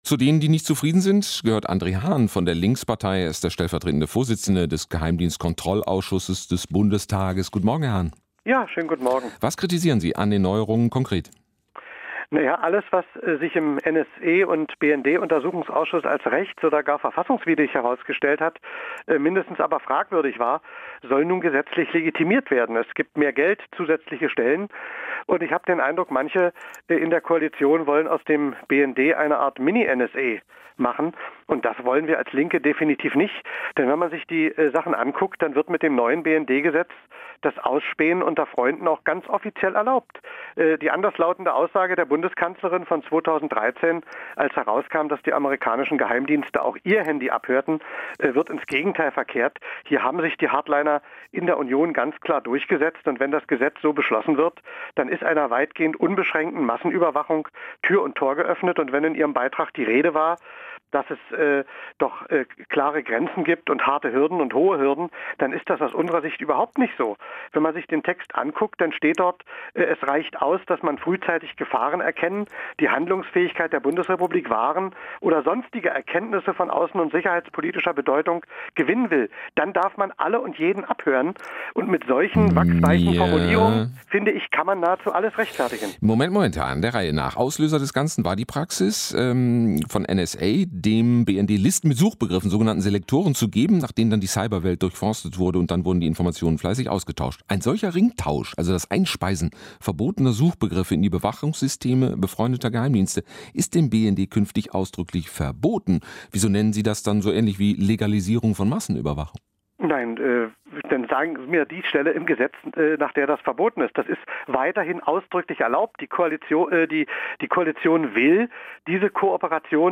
Wie viel Überwachung darf sein? | WDR 5 Morgenecho – Interview (21.10.2016)